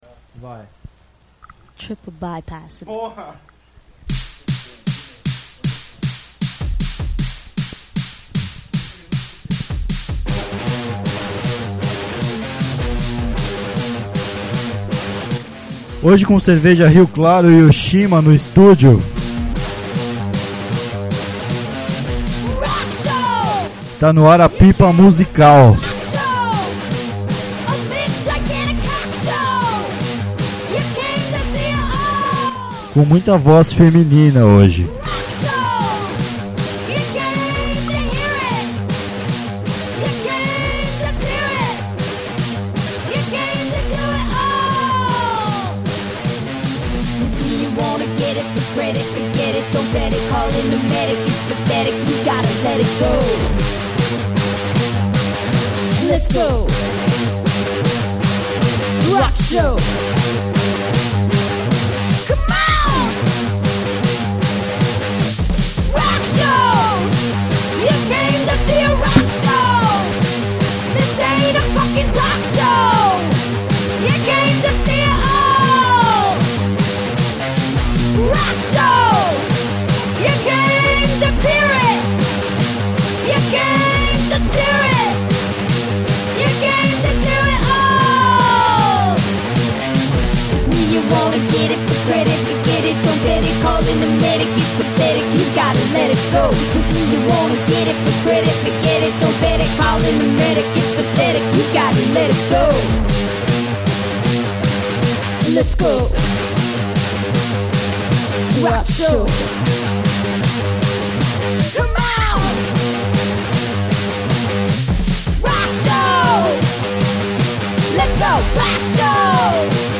Um monte de brasileirice que não é MPB e que faz balançar os corpos de quem tem bom ouvido, mas nunca é citado por aí.
Programa 5 estrelas (er, apesar das falhas do microfone).